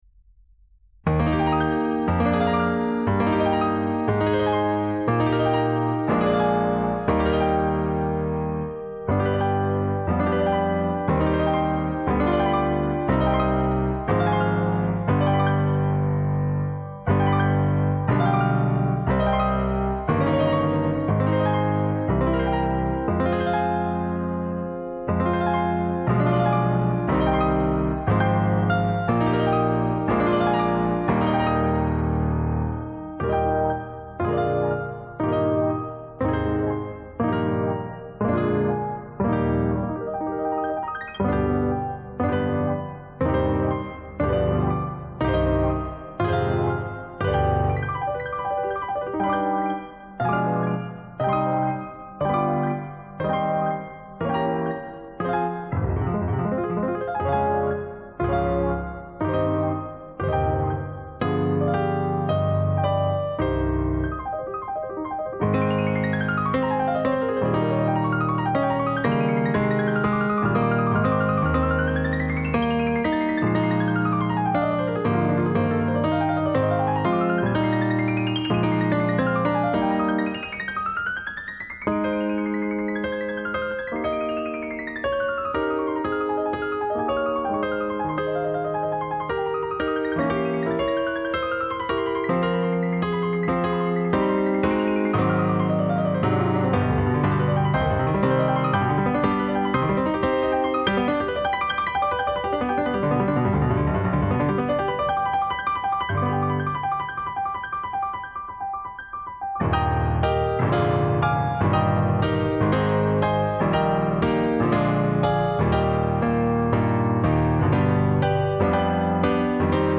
Piece: Allegro Maestoso, Composer: Carl Czerny, Suite: Das moderne Klavierspiel Op.837 No.19